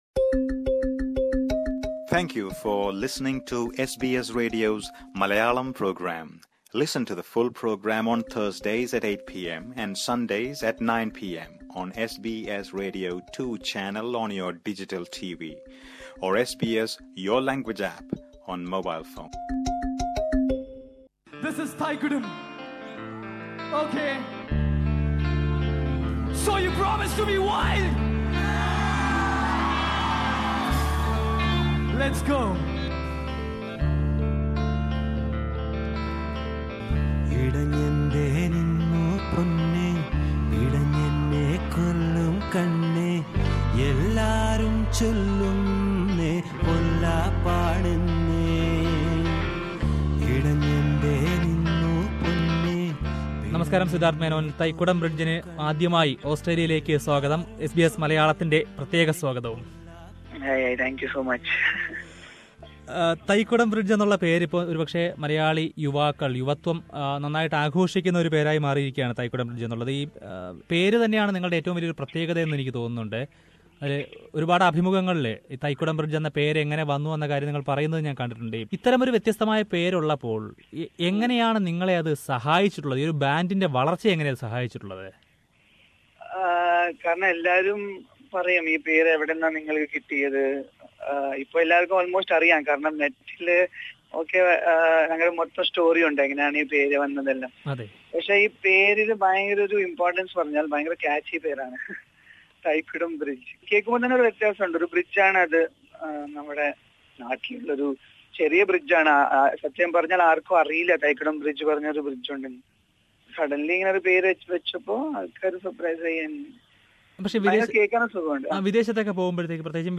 ഓസ്‌ട്രേലിയയിലെ ഷോകള്‍ തുടങ്ങുന്നതിന് മുമ്പായി തൈക്കുടം ബ്രിഡ്ജിലെ അംഗങ്ങള്‍ സിദ്ധാർത്ഥ് മേനോനും ഗോവിന്ദ് മേനോനും എസ് ബി എസ് മലയാളം റേഡിയോയുമായി സംസാരിച്ചു.